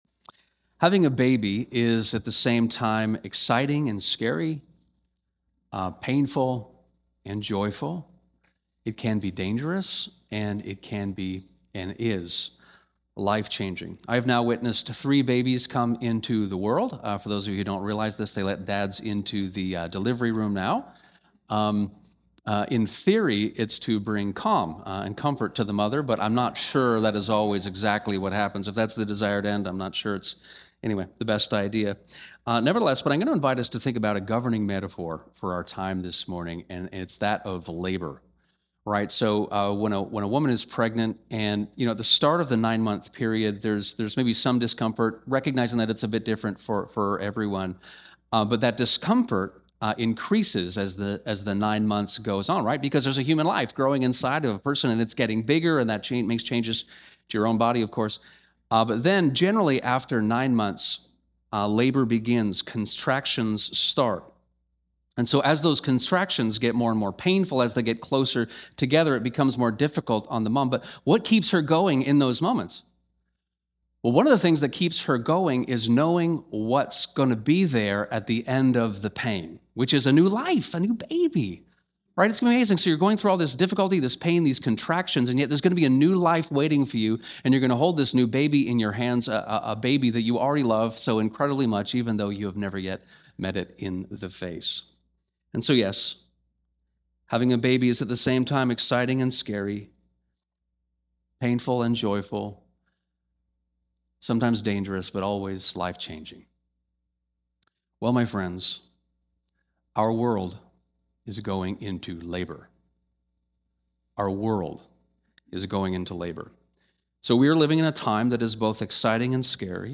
This sermon explores the first of Daniel’s four visions (Daniel 7), provides historical context to what he saw, and speaks to how this impacts us today—because it does.